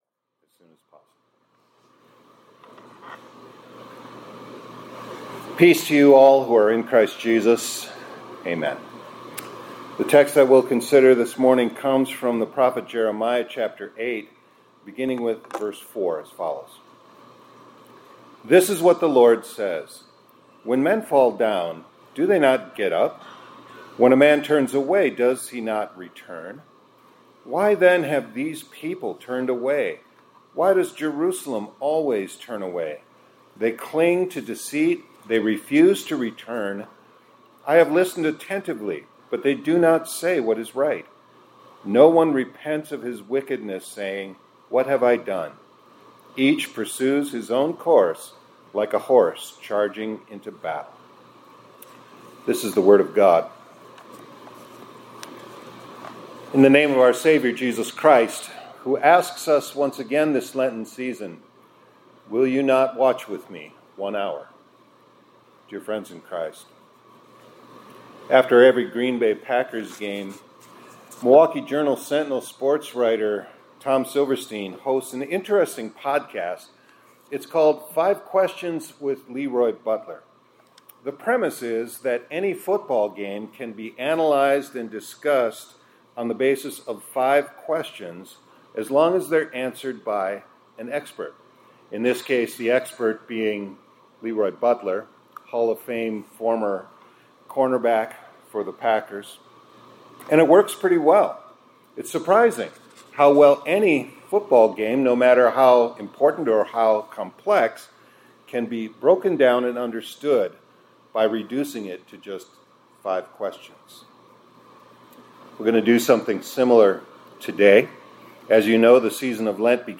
2026-02-18 ILC Chapel — Ash Wednesday in Two Questions